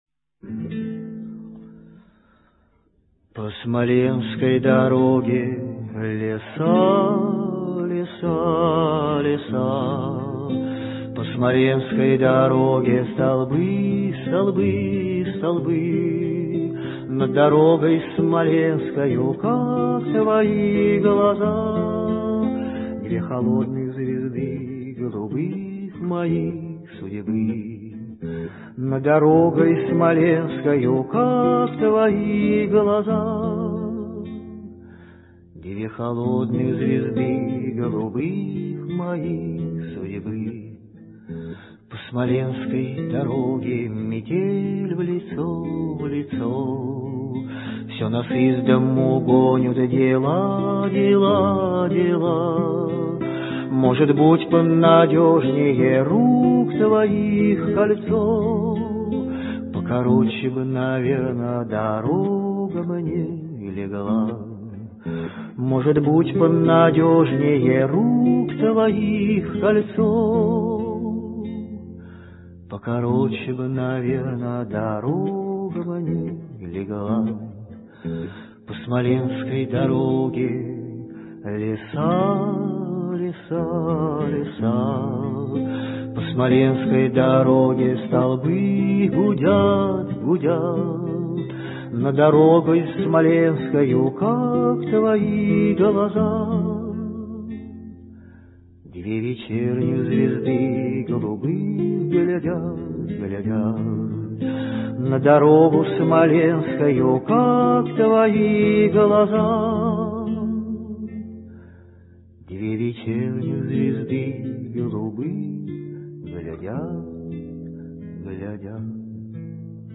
Слушаем песню в авторском исполнении (скачать):